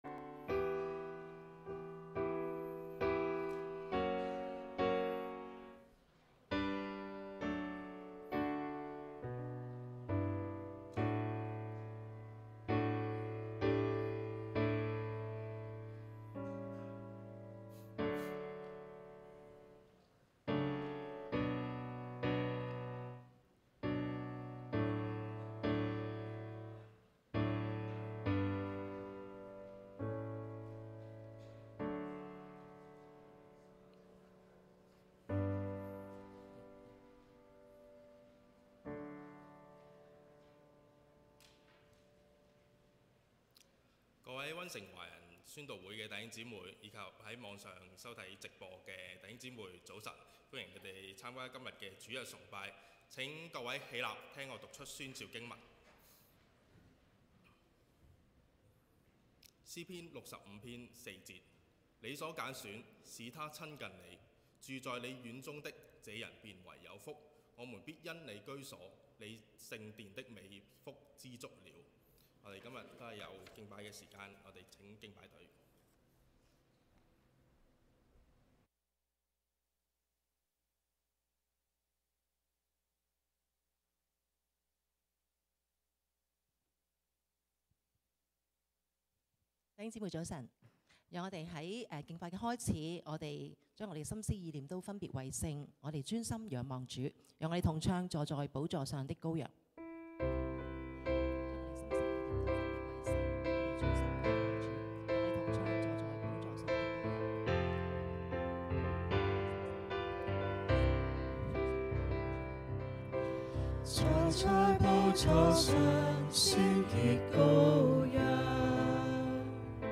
2026年2月15日溫城華人宣道會粵語堂主日崇拜